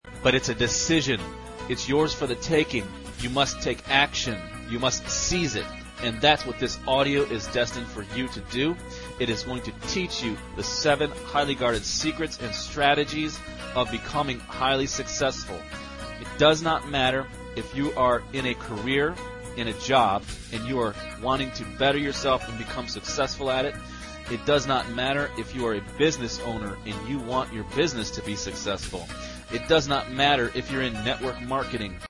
Complete MP3 Audiobook Total 47 minutes 56 seconds